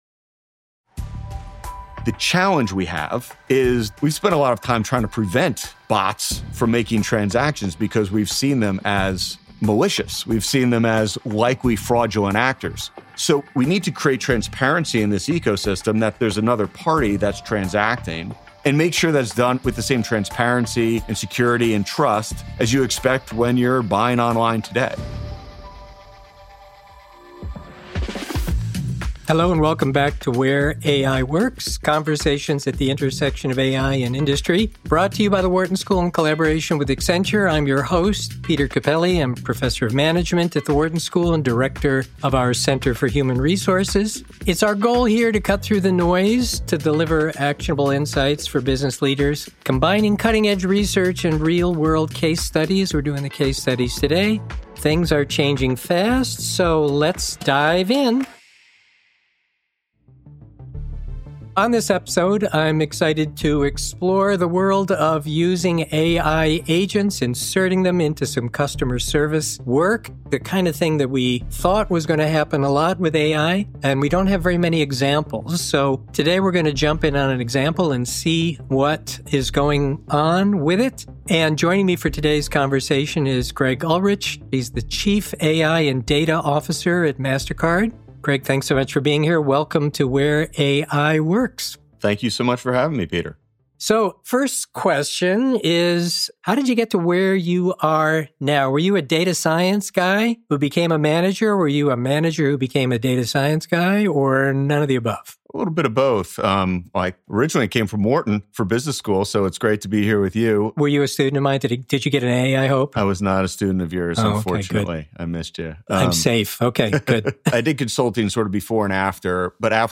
Conversations at the Intersection of AI and Industry